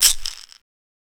Percs